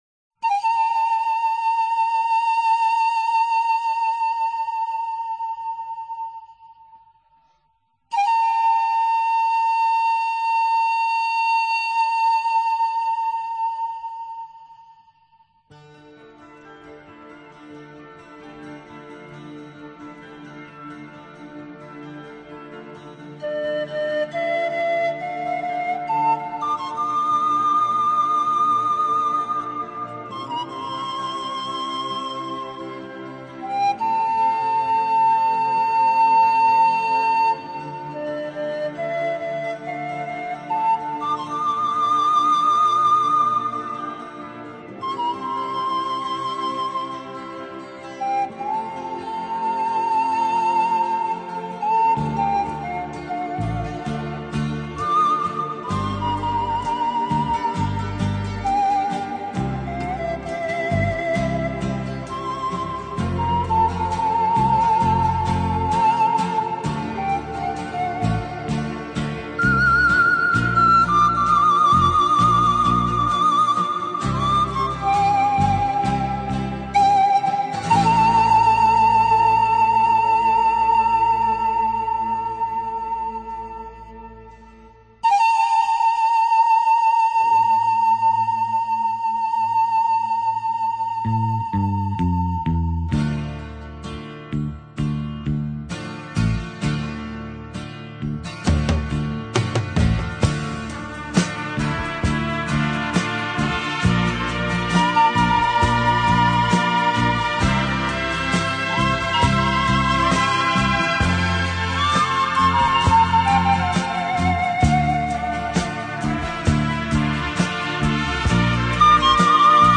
ADD/DDD STEREO
总体而言，音色温暖而和谐，并很好地体现出排箫那飘逸、悠远的气息